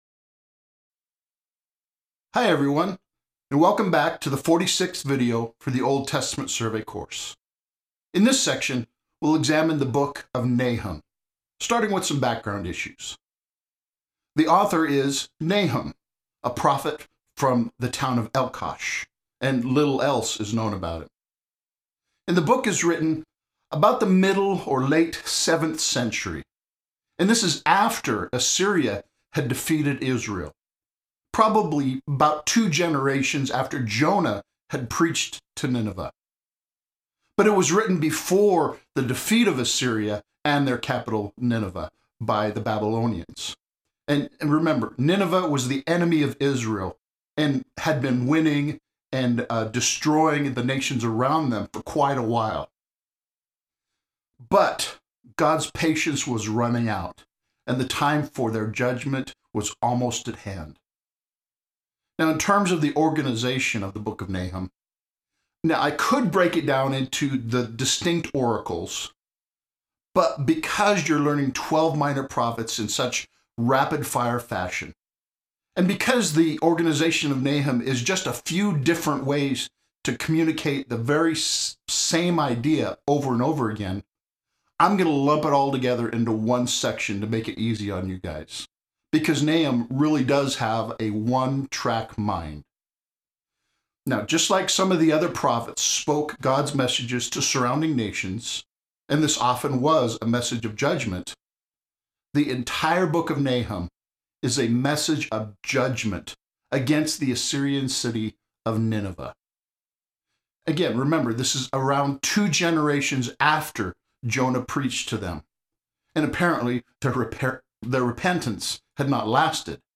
The second section has a survey video lecture covering the entire book.